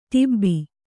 ♪ ḍabbi